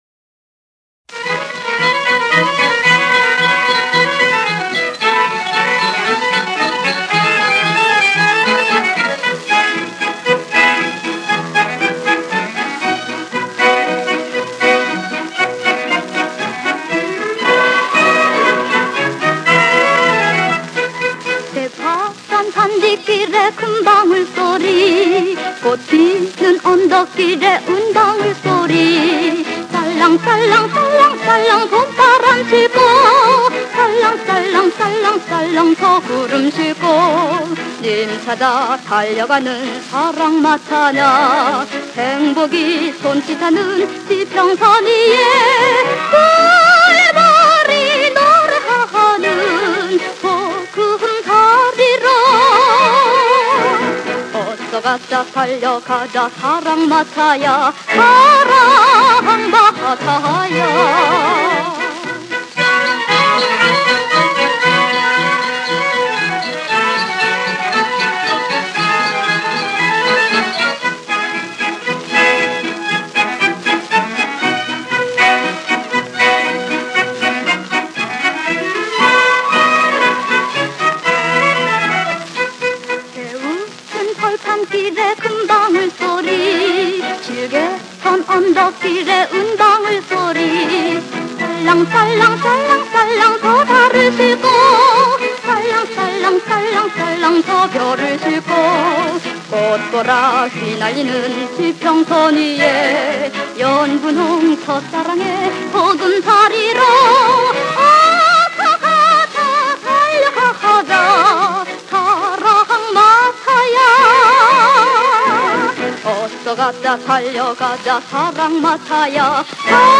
♠그때 그 시절 옛 가요/★50~60년(측음기)